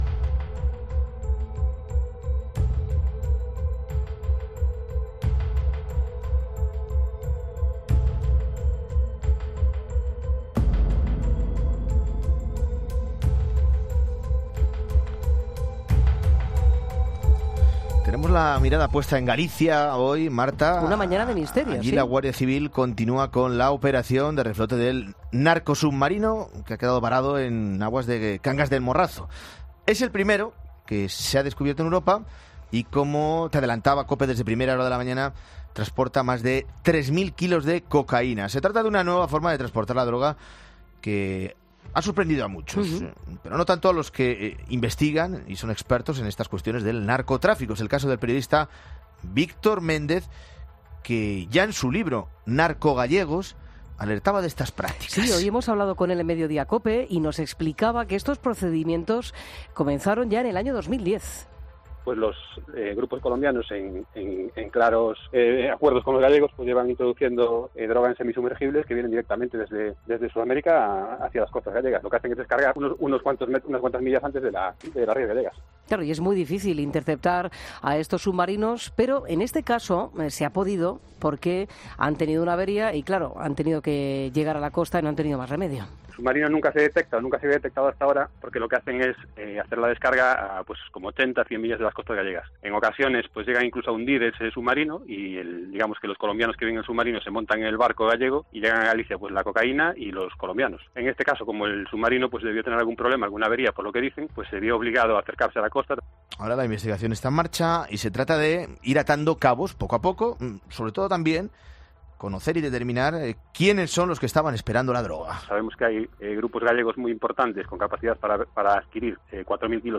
El periodista ha explicado en Mediodía COPE que estos procedimientos comenzaron en el 2010.